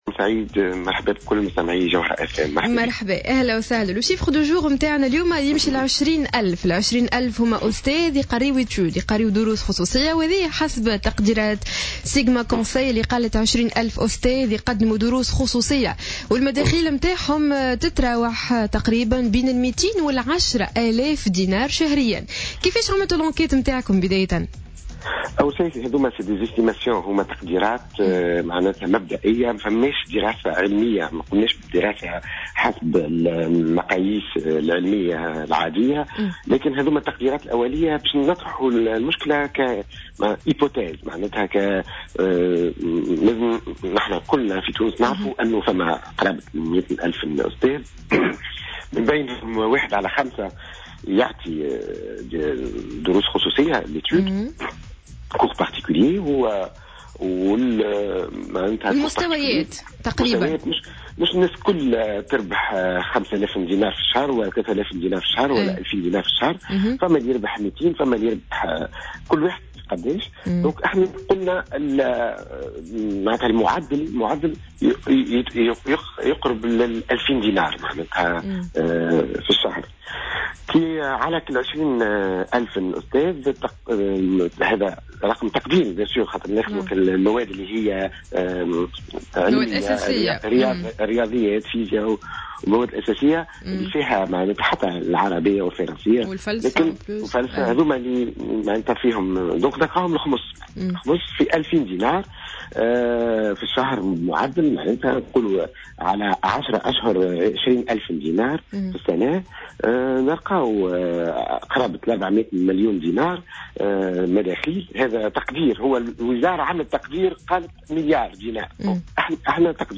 مداخلى له على جوهرة اف ام صباح اليوم...